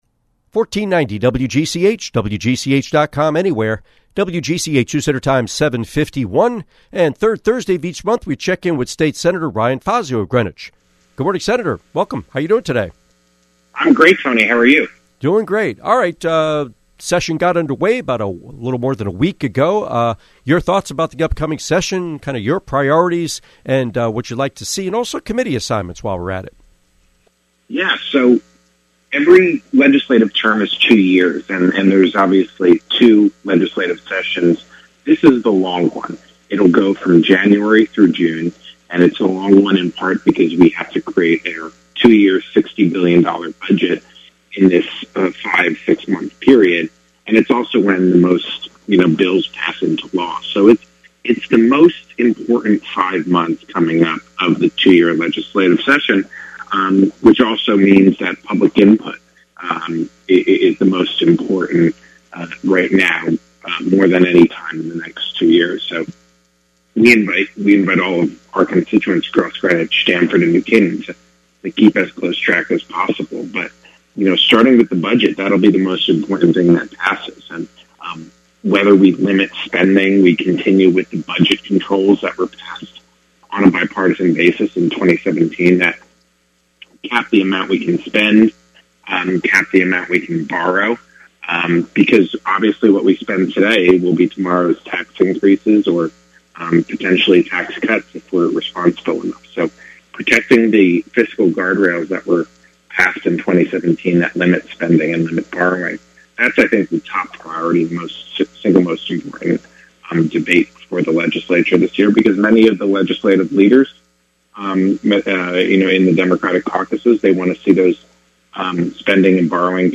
Interview with State Senator Fazio